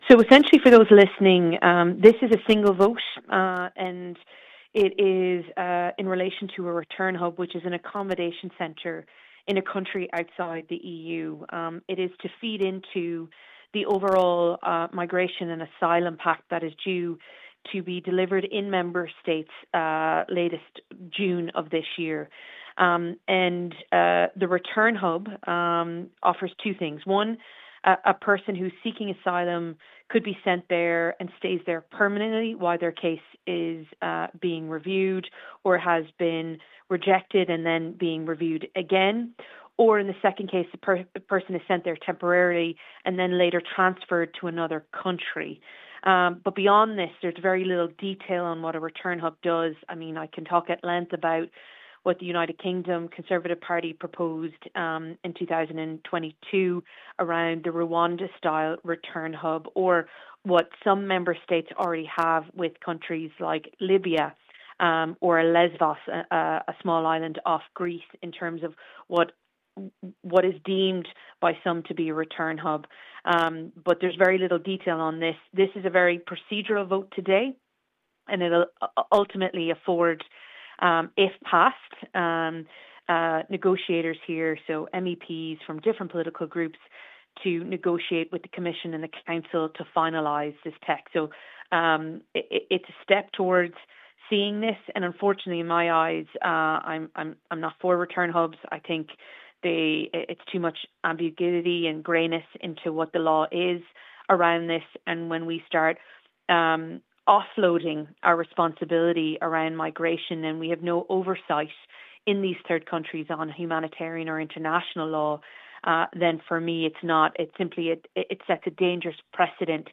Critical of return hubs in general Ms Walsh describes her critiques: